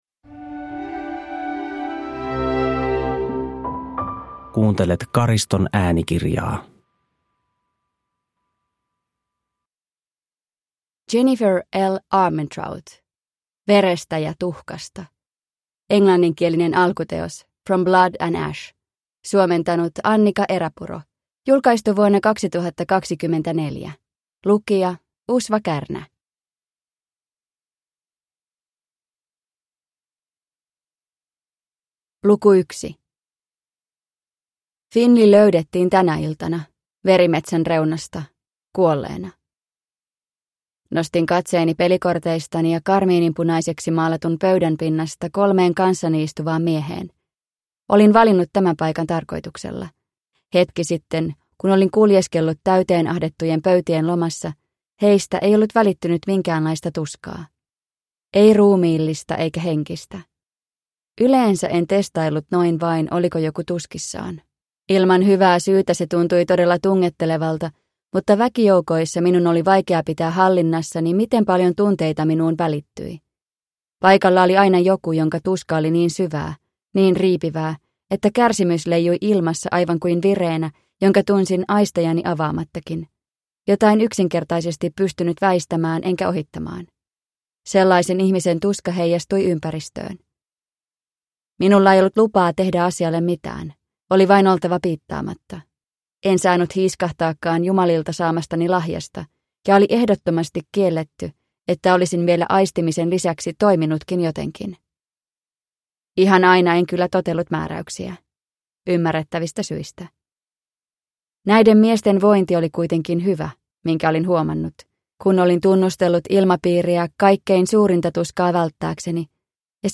Verestä ja tuhkasta (ljudbok) av Jennifer L. Armentrout